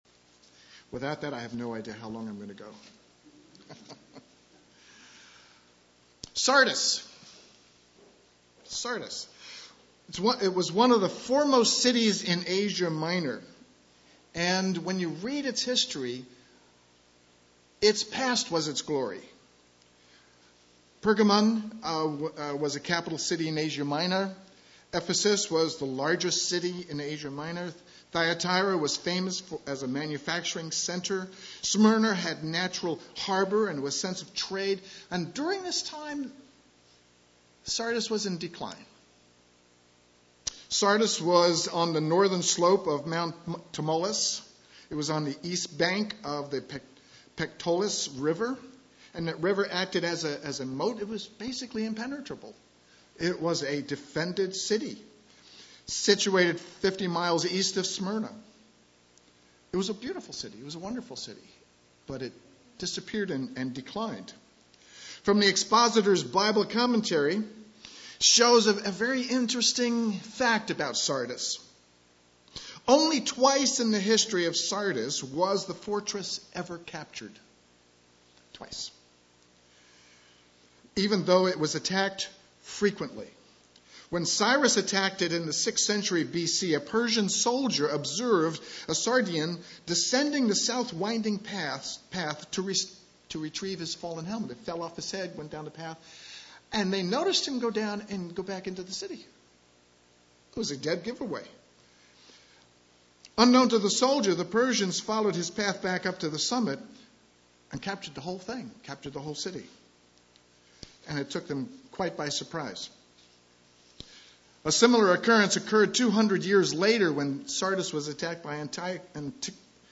Sermons
Given in Eureka, CA